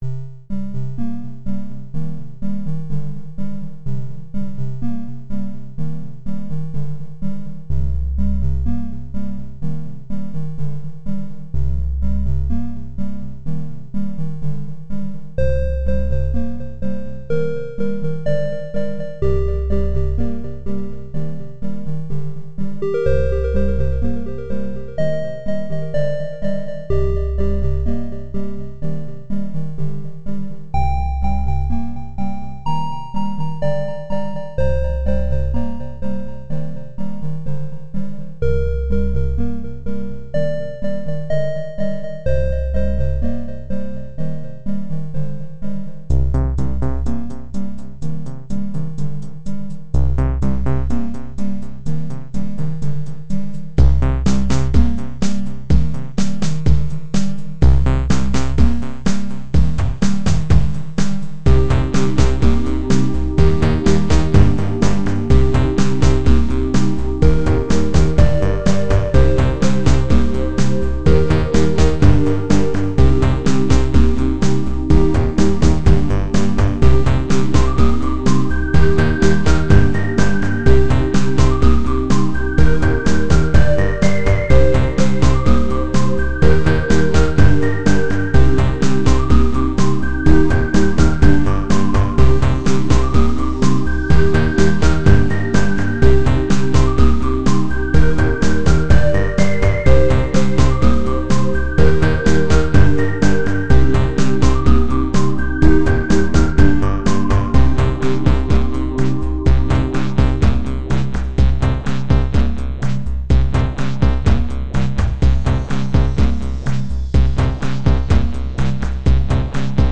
Relaxing nostalgic-sounding chippy track.
• Chip music
• Music requires/does smooth looping